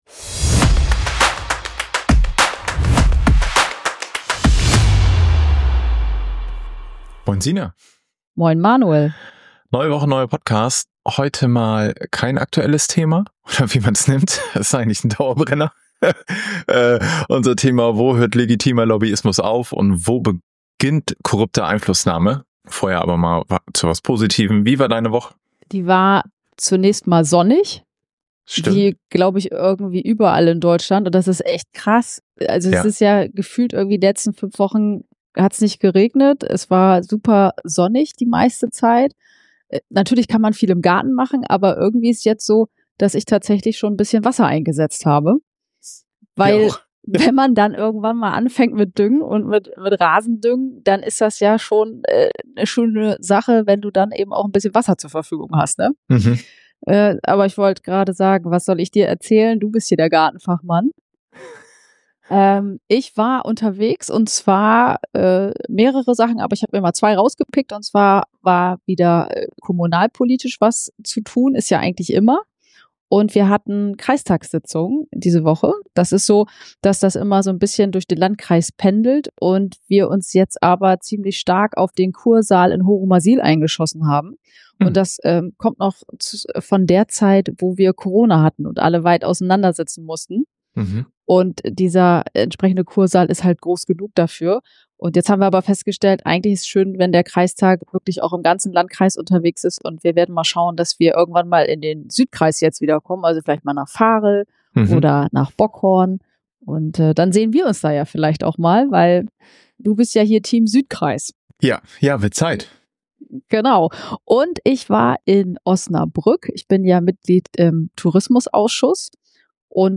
In lockerer Atmosphäre und mit einem überraschenden Quiz rund um Lobbyverbände nähern wir uns der zentralen Frage: Wie können wir das Vertrauen in politische Entscheidungen stärken und gleichzeitig legitimen Interessenvertretungen Raum geben?